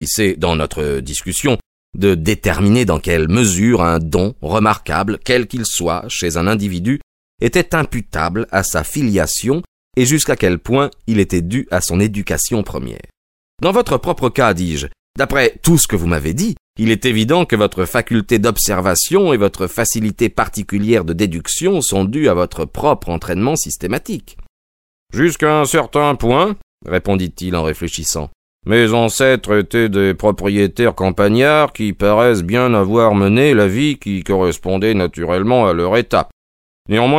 Cette adaptation audio est faite à la manière des grands raconteurs d'histoires, qui font revivre tous les personnages en les interprétant brillamment.